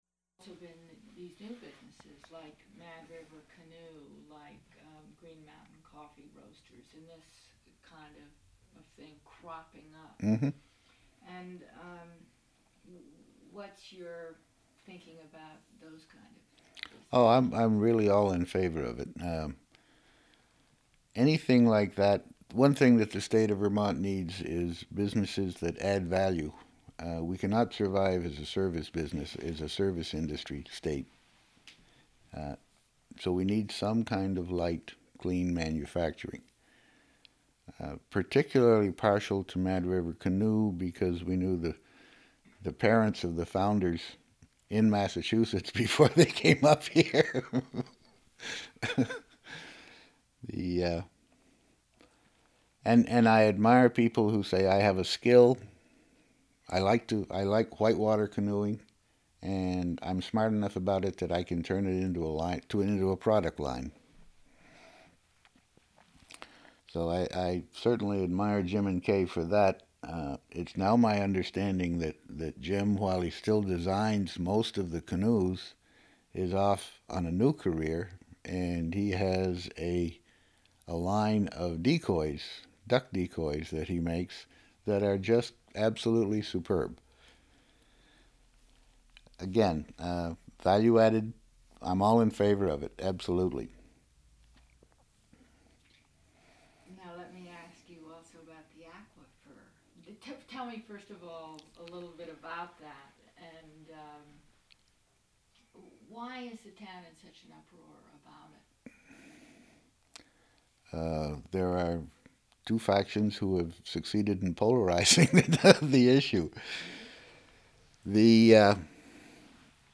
Interview
sound cassette (DAT)